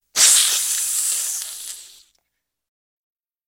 the-sound-of-hissing-snakes